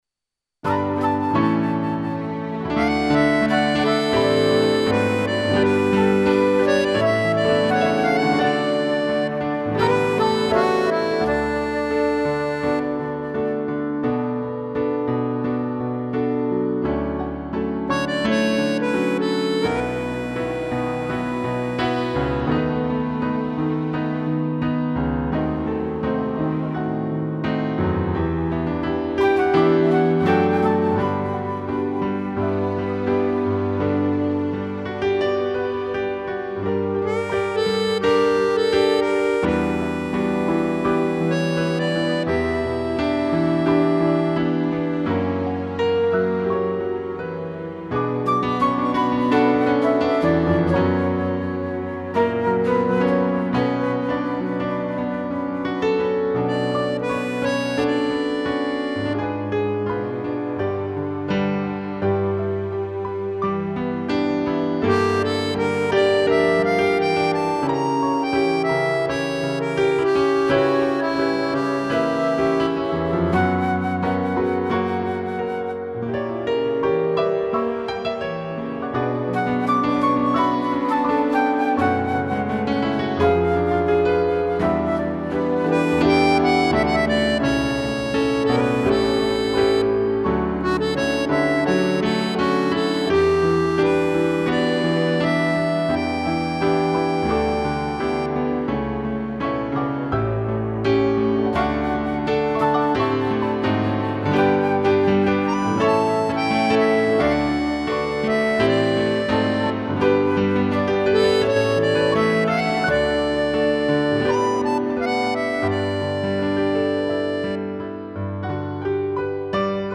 2 pianos, flauta, acordeão e cello
(instrumental)